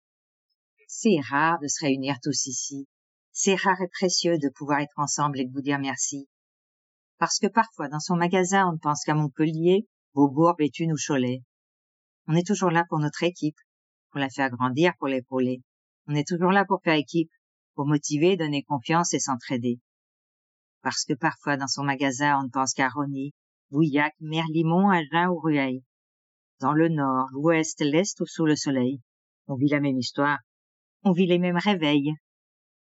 Voix off
Bandes-son
- Mezzo-soprano